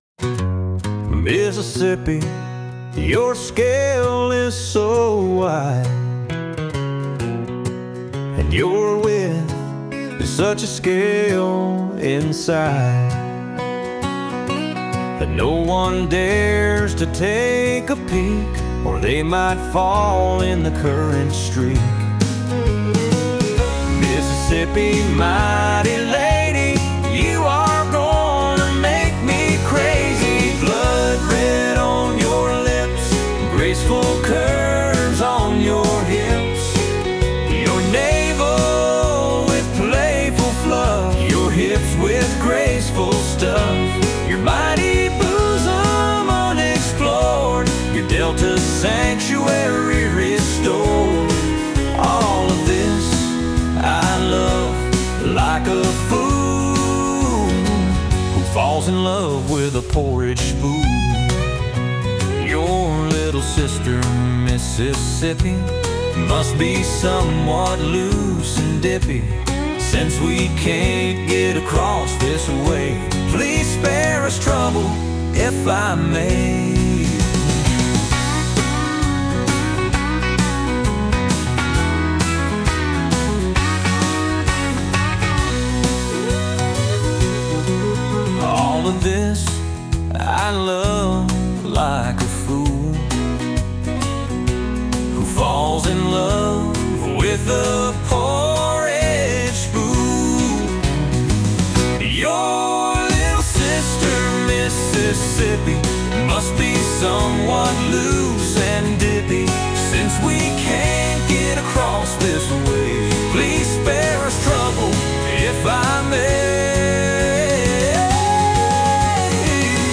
Audiobook: Horse Ranger I - Threatened Love